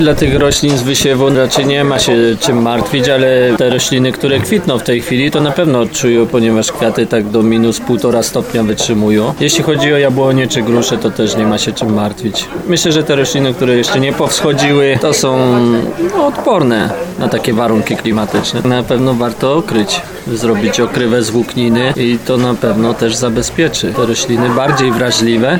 mówi jeden z podtarnowskich plantatorów.